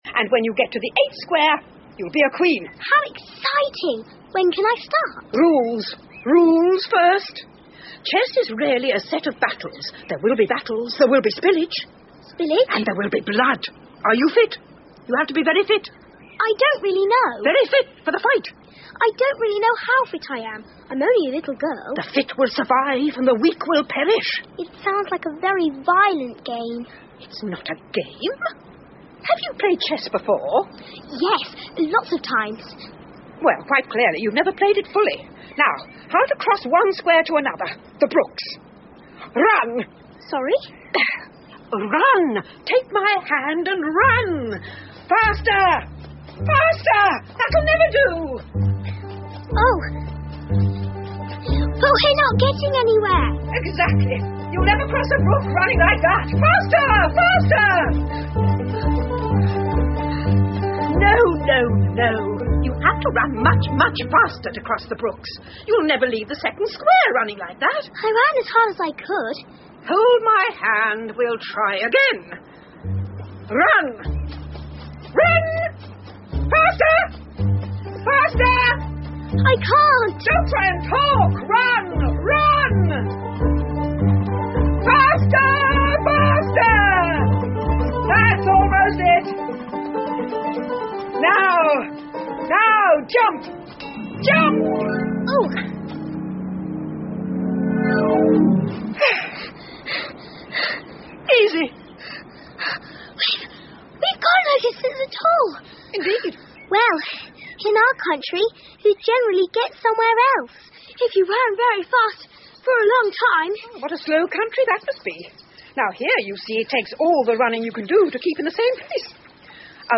Through The Looking Glas 艾丽丝镜中奇遇记 儿童广播剧 5 听力文件下载—在线英语听力室